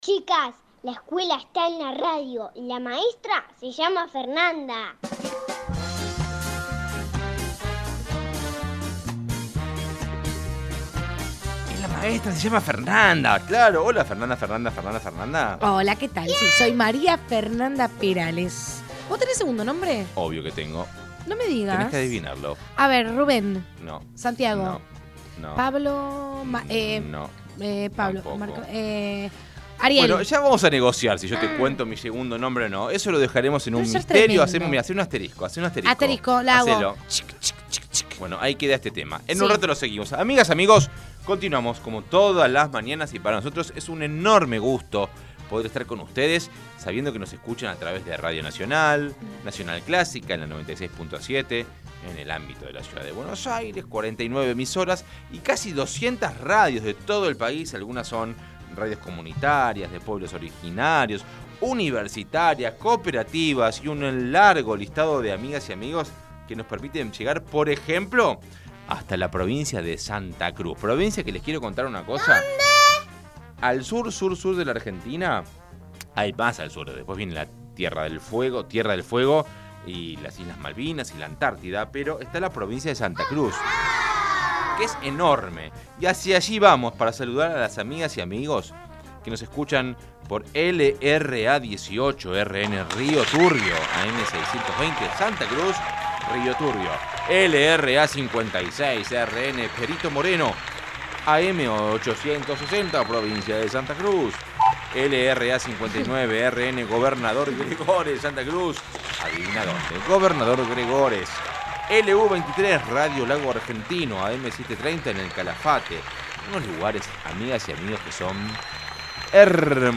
Ciclo Inicial Parte 1 Contenidos ¿Qué necesitamos para cocinar? Sonidos de la cocina.
Receta cantada.